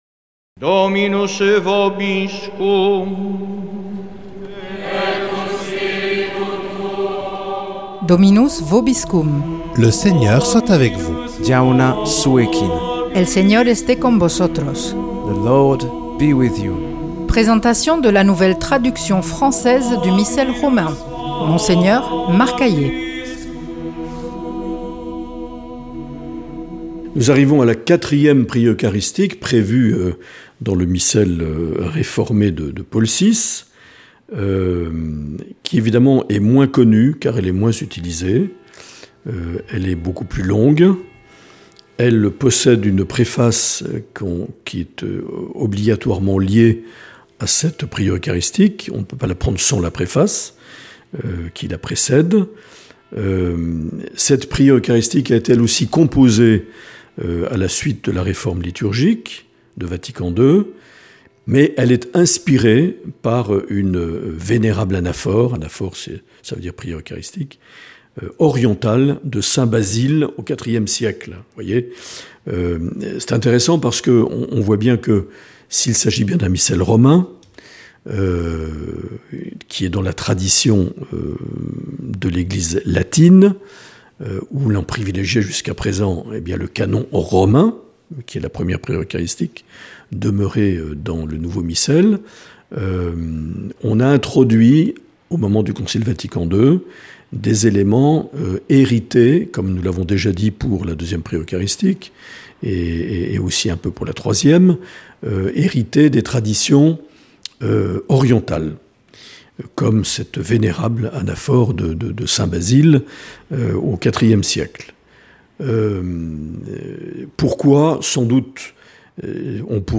Présentation de la nouvelle traduction française du Missel Romain par Mgr Marc Aillet
Une émission présentée par